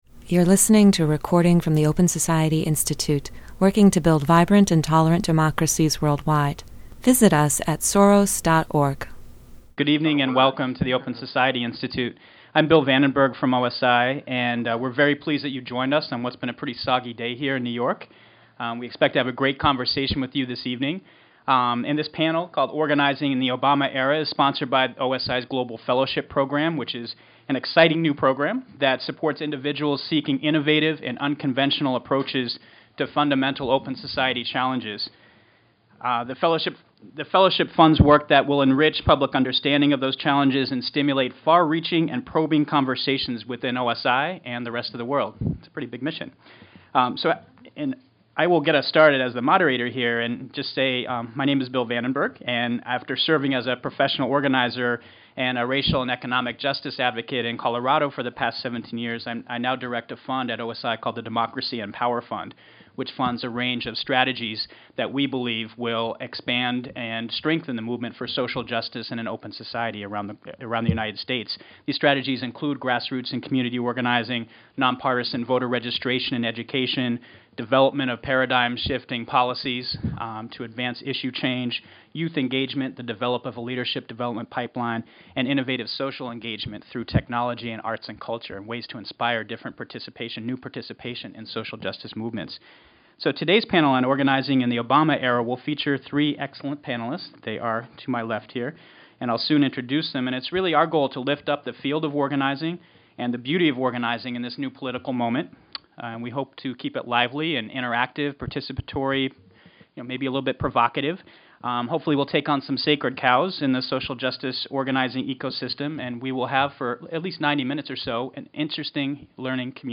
Organizing in the Obama Era (May 7, 2009) Download MP3 The Obama campaign vividly demonstrated the power of mass civic participation. But many organizers still struggle with questions of efficacy and legitimacy. Panelists addressed the following questions: Can we mobilize large groups of people while also fostering a sense of engagement by individual participants?